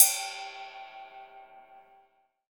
D2 RIDE-10.wav